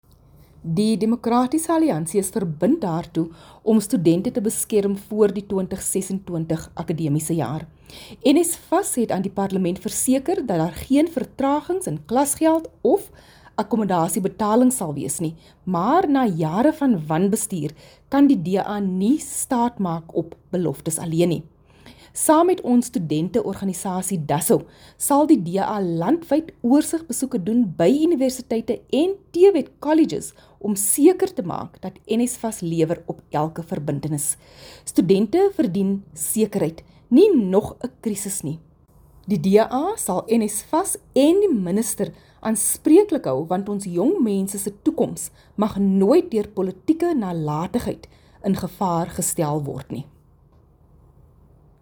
Afrikaans soundbites by Dr Delmaine Christians MP.